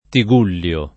[ ti g2 ll L o ]